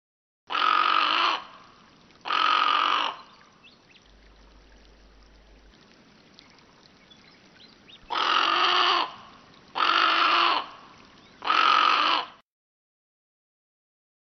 White-faced Heron
Songs & Calls
Listen to the call of the white-faced heron.